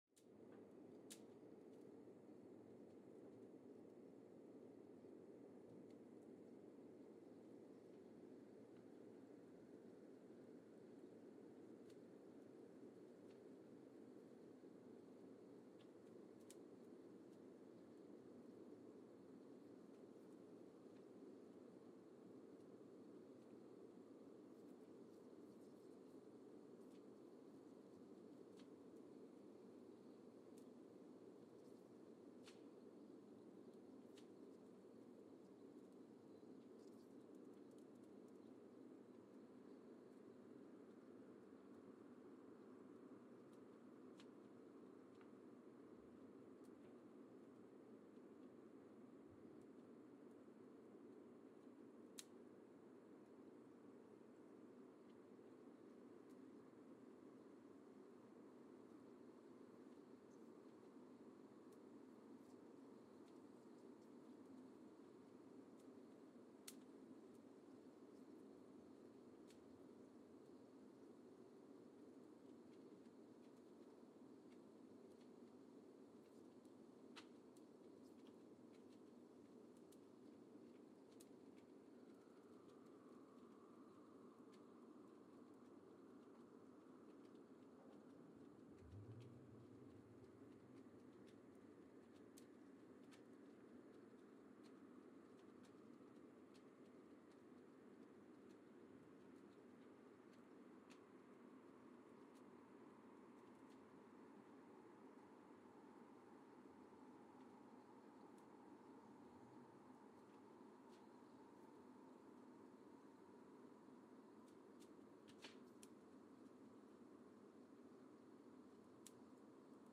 Mbarara, Uganda (seismic) archived on April 26, 2018
Station : MBAR (network: IRIS/IDA) at Mbarara, Uganda
Sensor : Geotech KS54000 triaxial broadband borehole seismometer
Speedup : ×1,800 (transposed up about 11 octaves)
Loop duration (audio) : 05:36 (stereo)